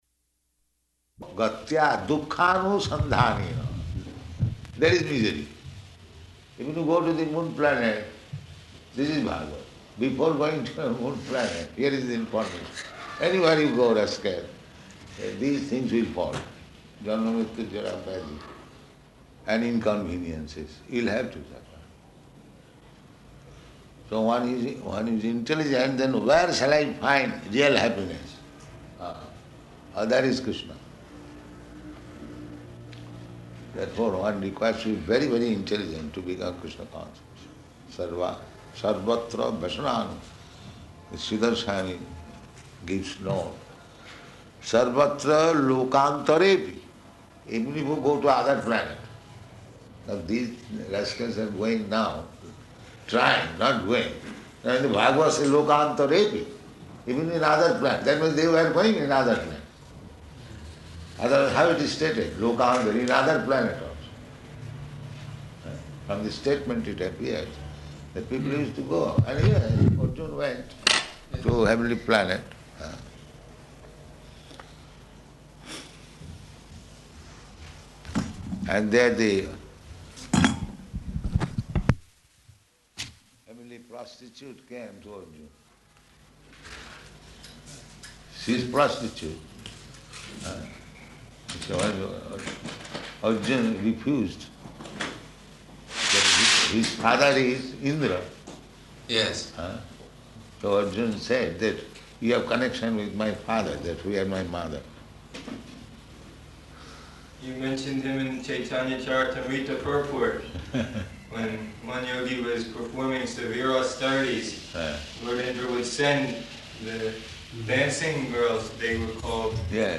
Room Conversation
Room Conversation --:-- --:-- Type: Conversation Dated: November 1st 1973 Location: Delhi Audio file: 731101R1.DEL.mp3 Prabhupāda: ...gatvā duḥkhānusaṅgaminaḥ.